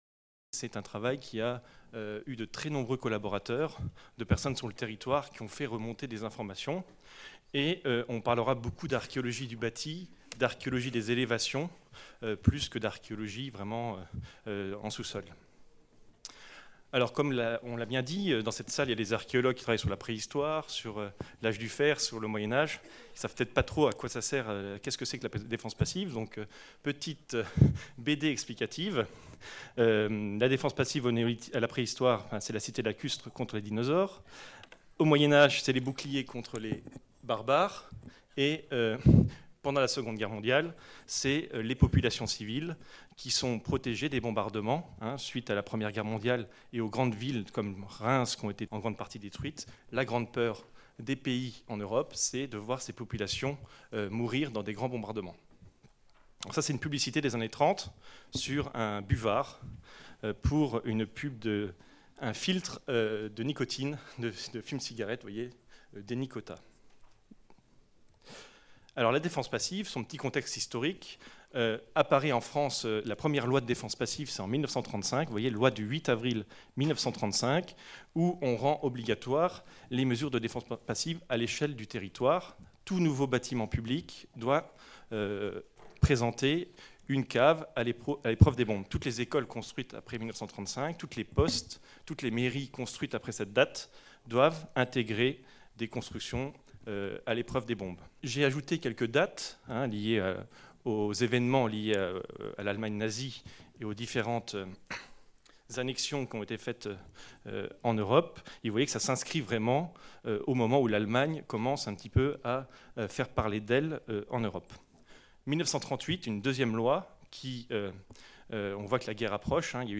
Cette communication a été filmée lors du colloque international intitulé De Verdun à Caen - L'archéologie des conflits contemporains : méthodes, apports, enjeux qui s'est déroulé au Mémorial de Caen les 27 et 28 mars 2019, organisé par la DRAC Normandie, la DRAC Grand-Est, l'Inrap et l'Université de Caen (MRSH-HisTeMé) avec le partenariat de la Région Normandie, du Département du Calvados, de la Ville de Caen et du Groupe de recherches archéologiques du Cotentin.